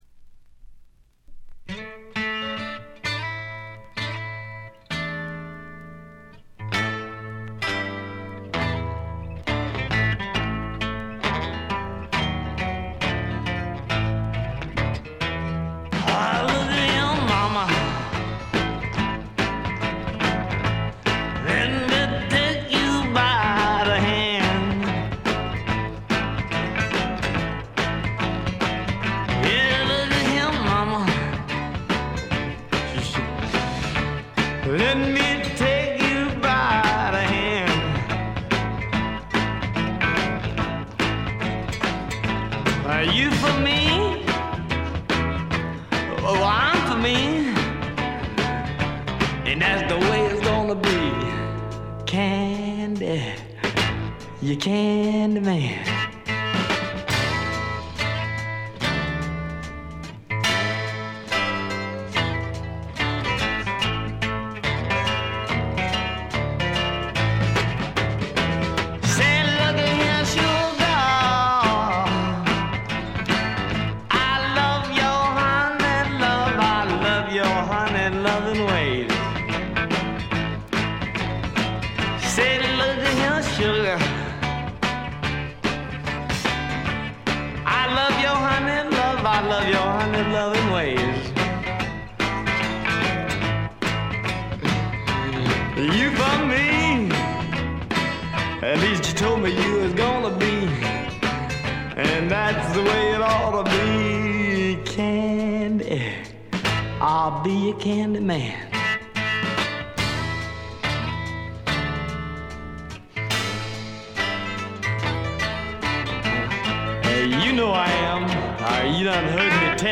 ほとんどノイズ感無し。
文句なしのスワンプ名盤。
試聴曲は現品からの取り込み音源です。